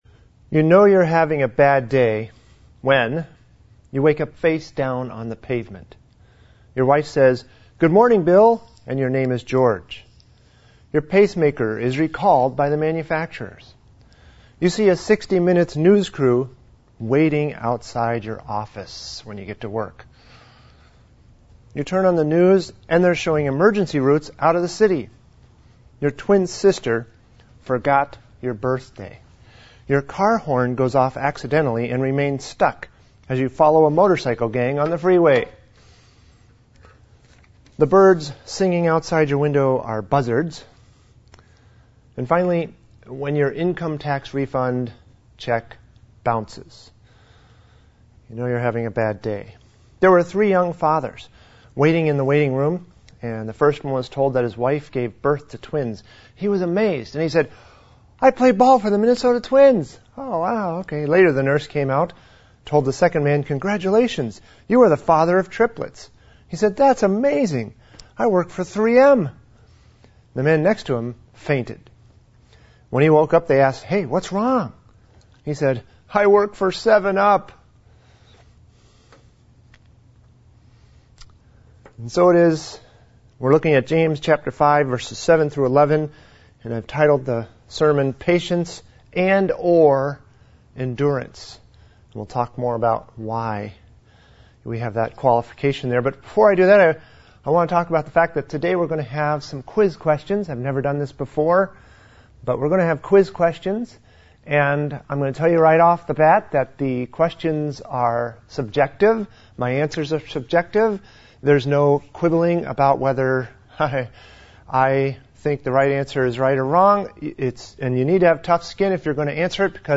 Sermons | Hartford Bible Church